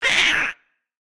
Minecraft Version Minecraft Version snapshot Latest Release | Latest Snapshot snapshot / assets / minecraft / sounds / mob / ghastling / hurt3.ogg Compare With Compare With Latest Release | Latest Snapshot
hurt3.ogg